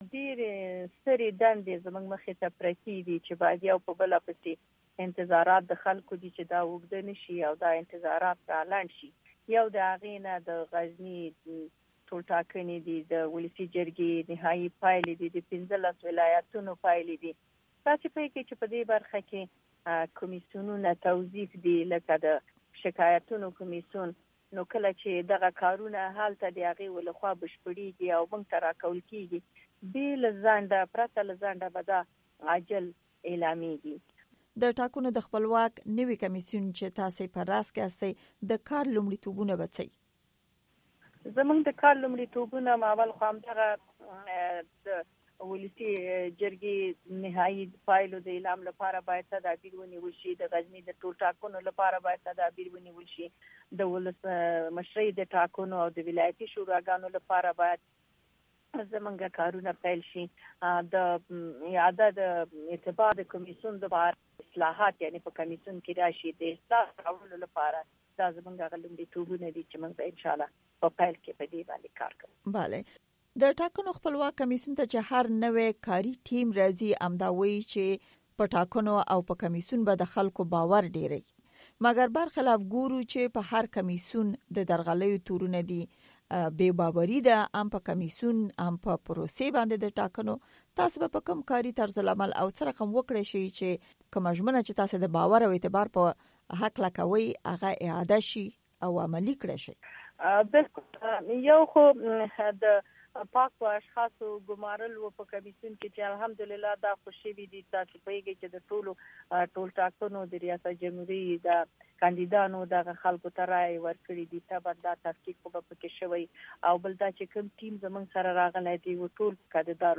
مرکې
د ټاکنو د خپلواک کمیسیون له نوې مشرې حوا علم نورستانۍ سره مرکه